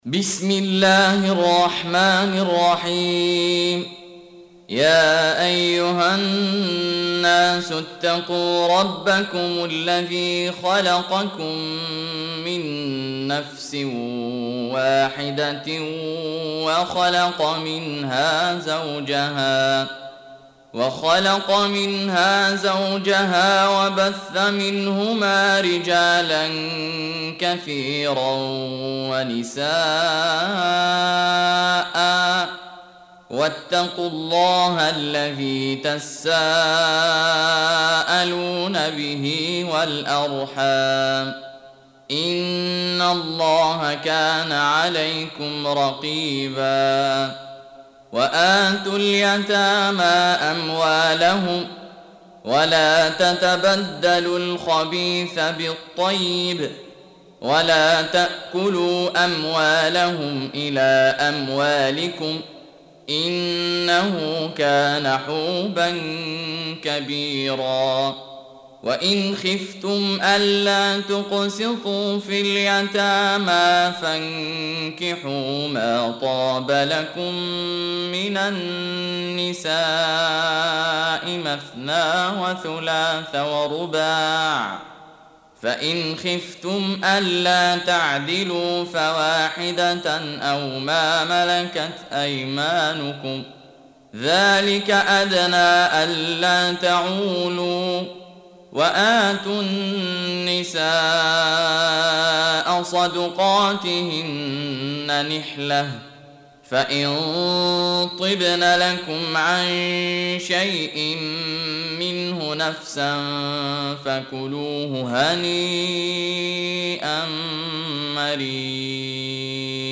Audio Quran Tarteel Recitation
حفص عن عاصم Hafs for Assem